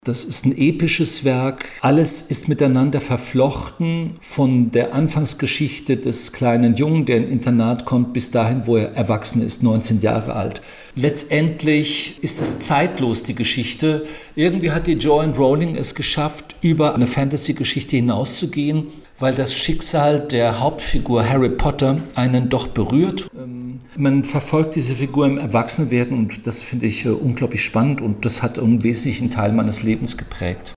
25 Jahre Harry Potter - Rufus Beck im Interview - PRIMATON